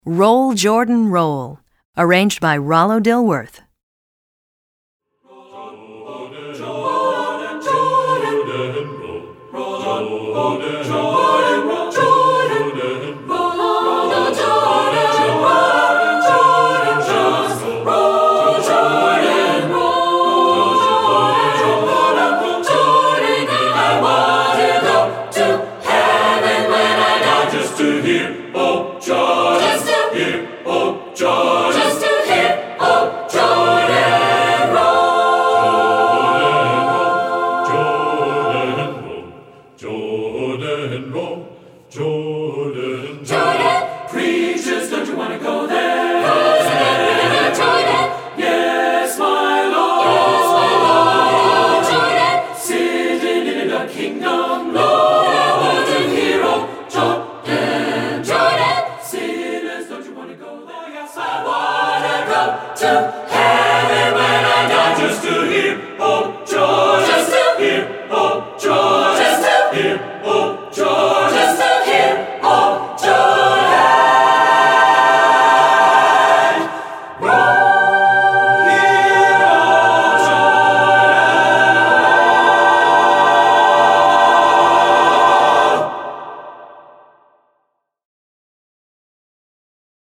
Composer: African-American Spiritual
Voicing: SATB a cappella